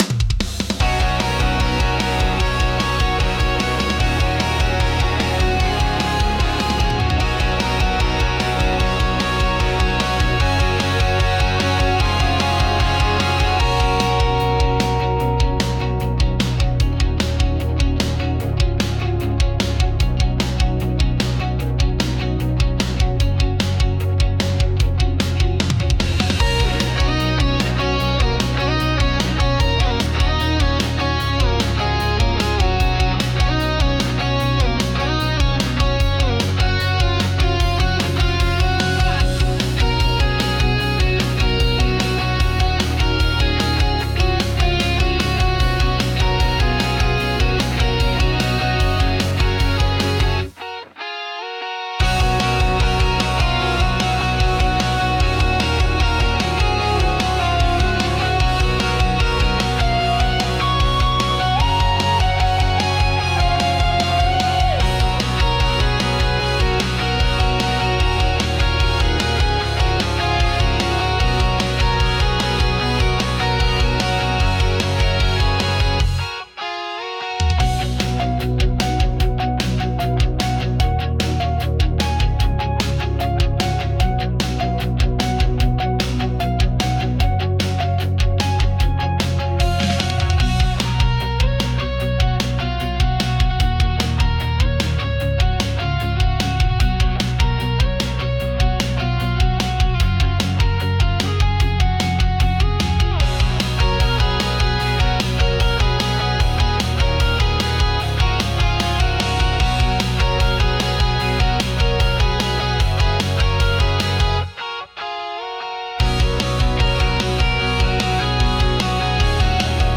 Genre: Pop Punk Mood: Upbeat Editor's Choice